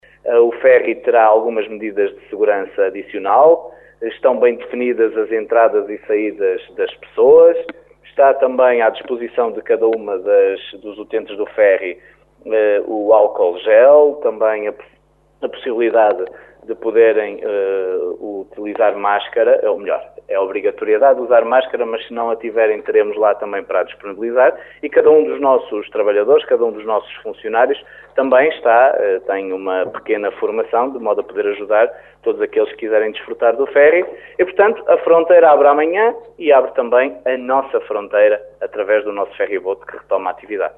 O ferry retoma esta quarta-feira as travessias normais mas com novas regras e medidas de segurança como explica o presidente da autarquia caminhense.